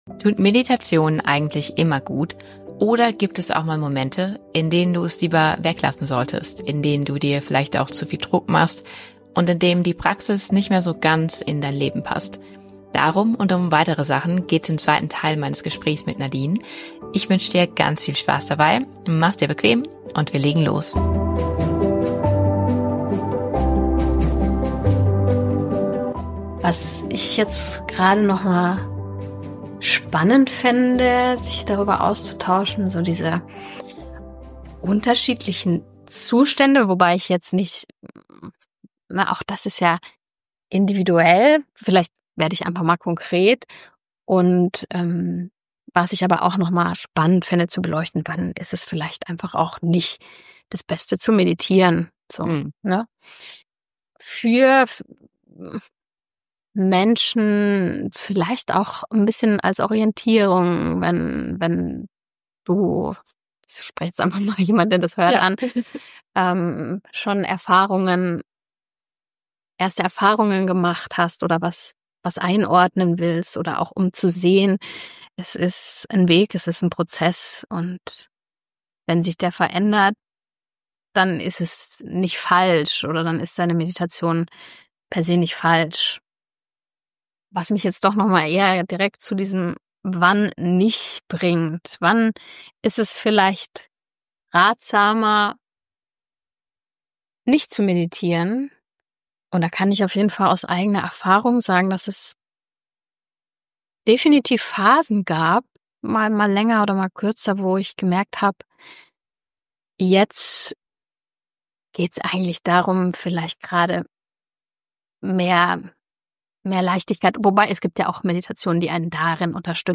Real Talk über Meditation